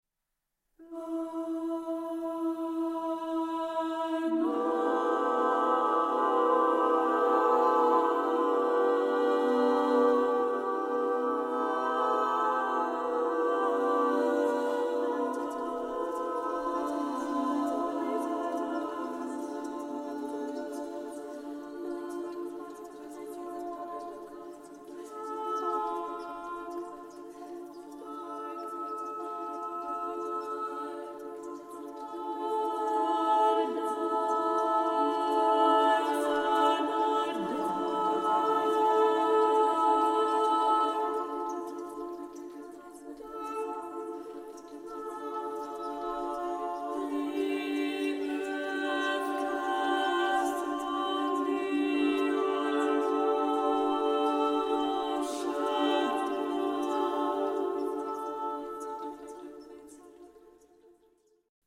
treble voice choir
Recorded August 8, 2021, Mechanics Hall, Worcester, MA